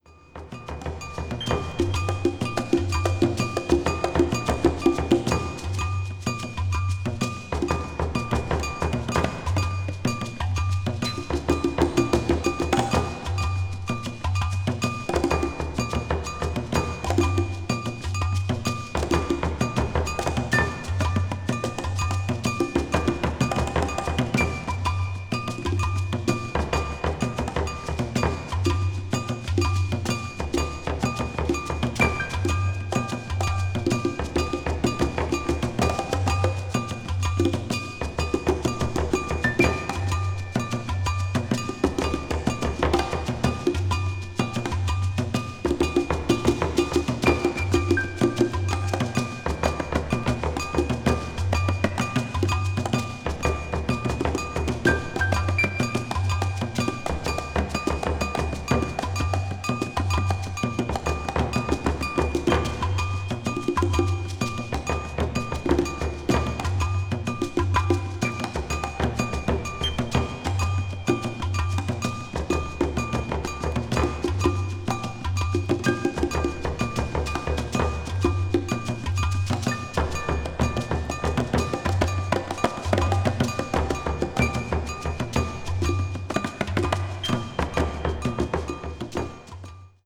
A面にセンターホールが少しずれているため、音にわずかな揺れがありますので、あらかじめご了承のうえお買い求めください。